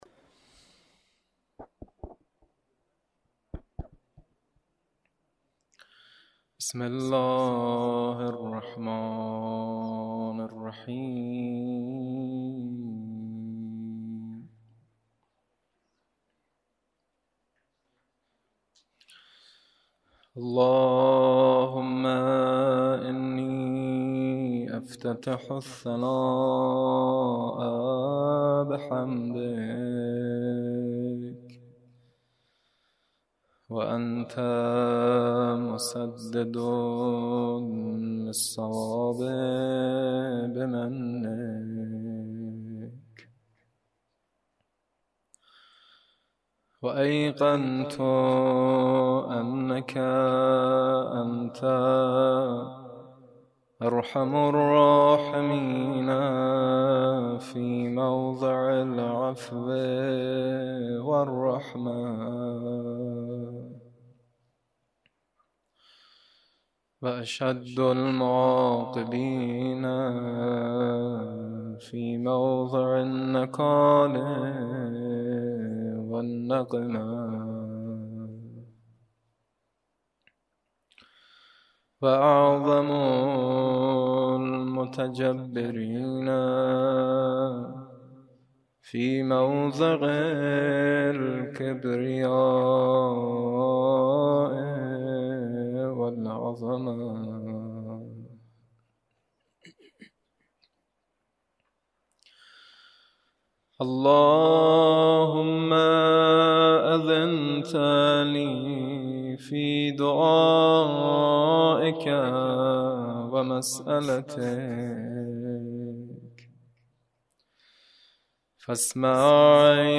قرائت دعای افتتاح 1
مداح